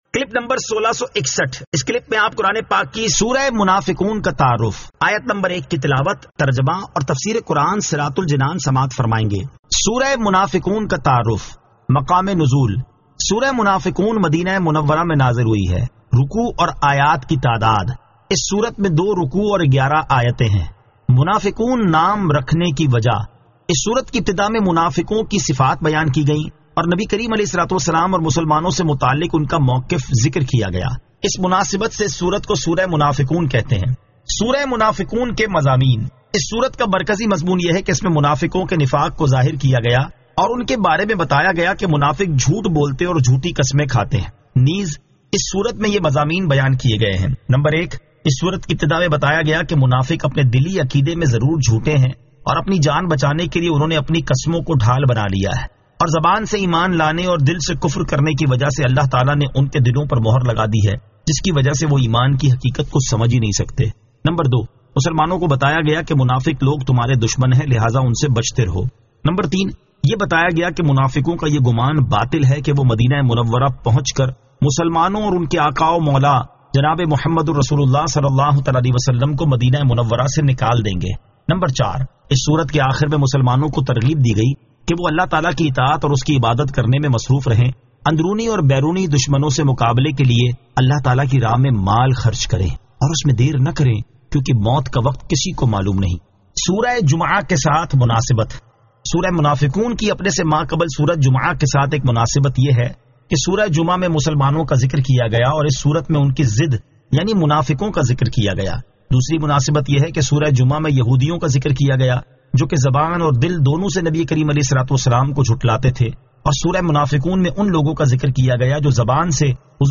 Surah Al-Munafiqun 01 To 01 Tilawat , Tarjama , Tafseer